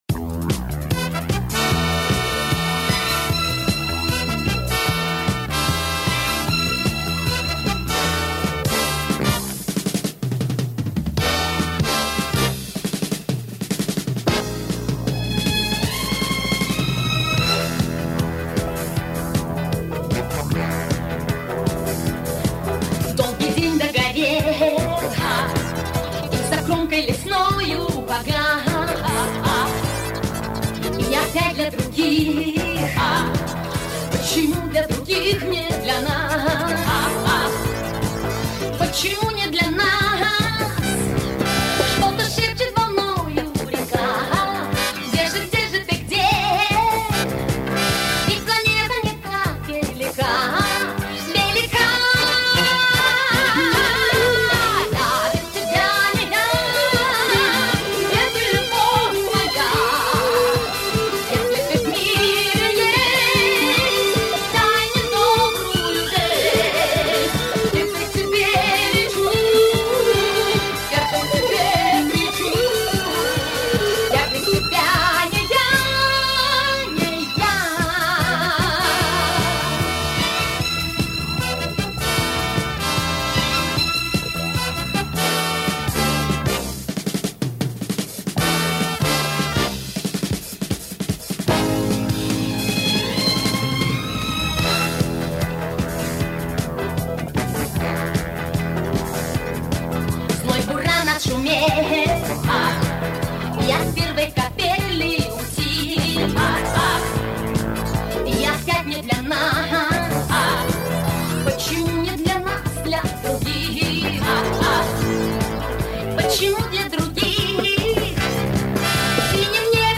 Режим: Stereo